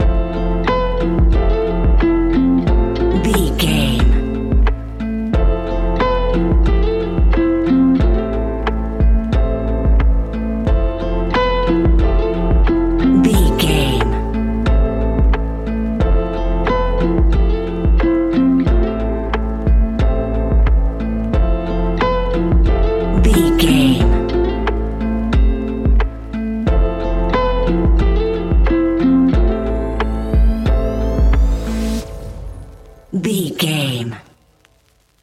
Ionian/Major
A♭
laid back
Lounge
sparse
new age
chilled electronica
ambient
atmospheric